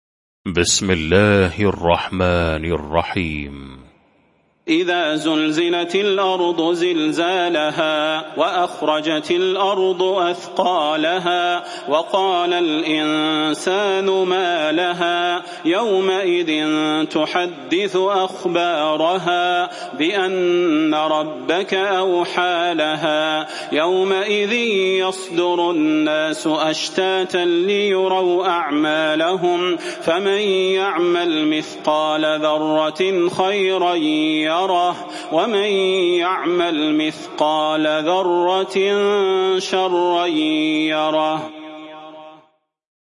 المكان: المسجد النبوي الشيخ: فضيلة الشيخ د. صلاح بن محمد البدير فضيلة الشيخ د. صلاح بن محمد البدير الزلزلة